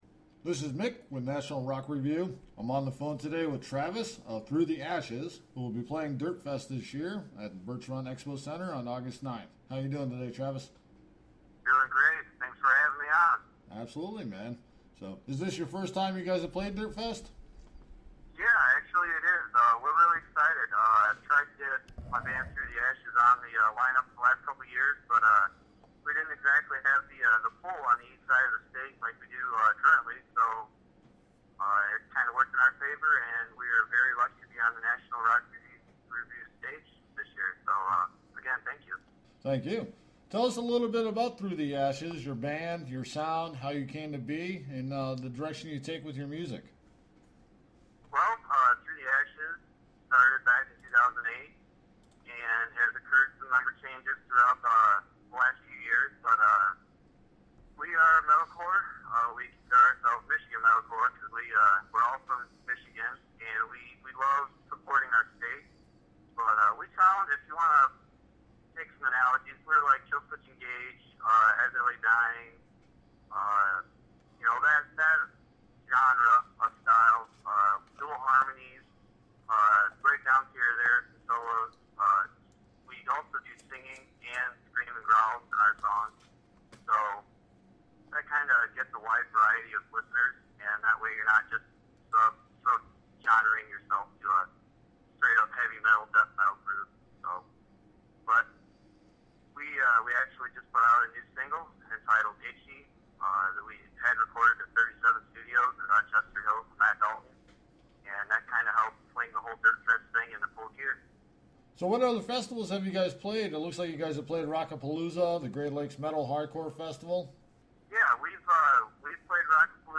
Dirt Fest Featured Artist: Through The Ashes + Interview